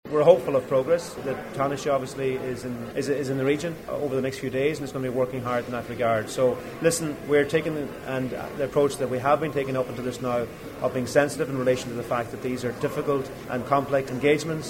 Agriculture Minister Charlie McConologue says this is a complex diplomatic process: